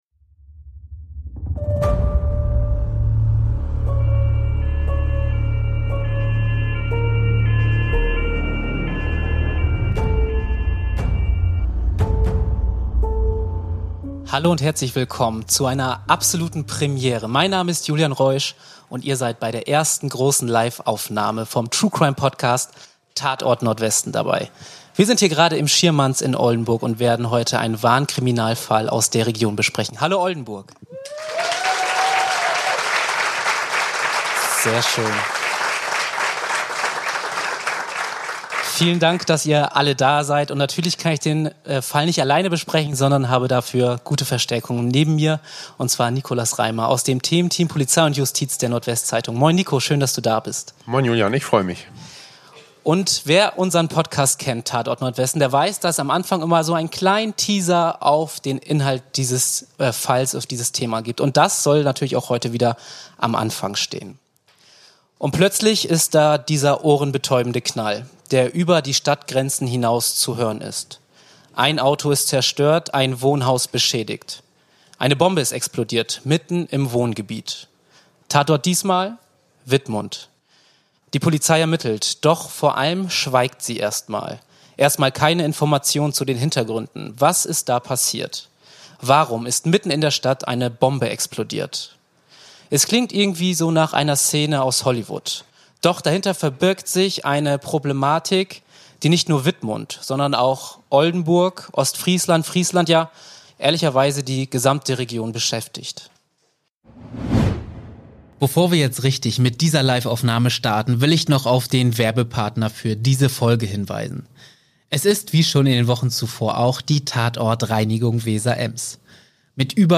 1 Clans der Region #63 (LIVE) 1:05:11